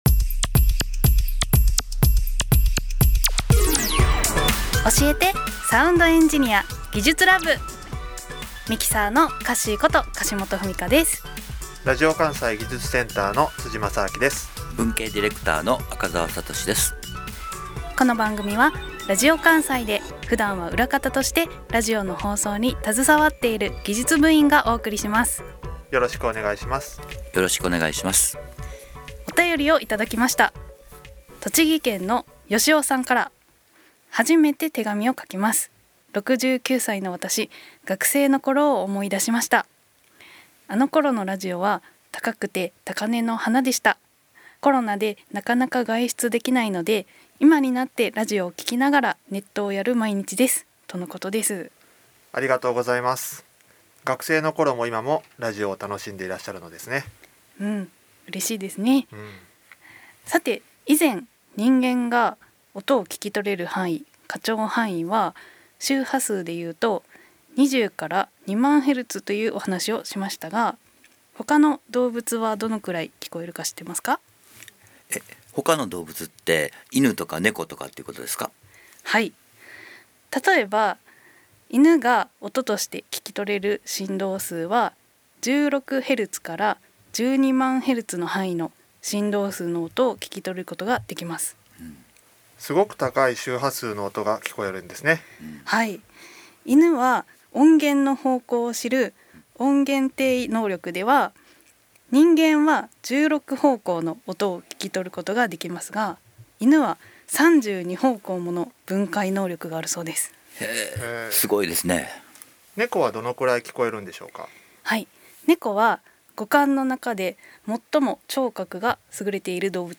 今回は動物の聴覚について、普段はラジオを陰で支えている技術スタッフが、ラジオ番組のなかで解説しました。
【放送音声】2021年10月31日放送回